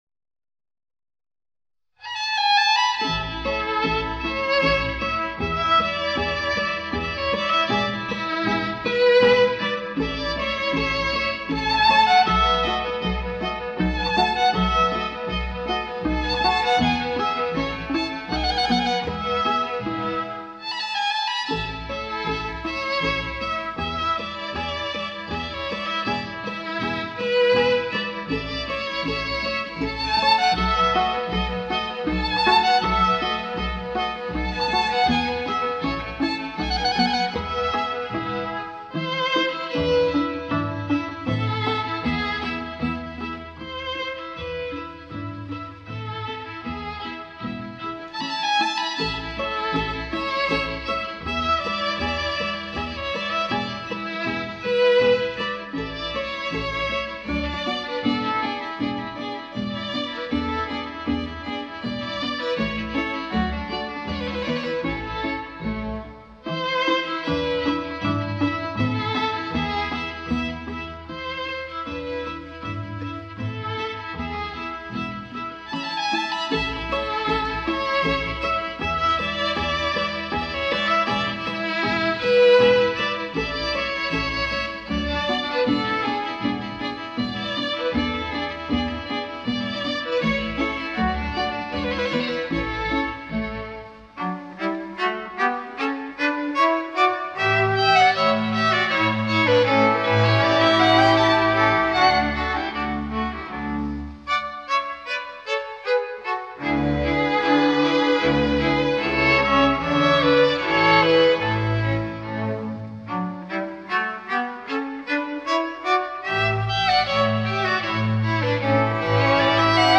是一首轻松愉快，抒情明澈的乐曲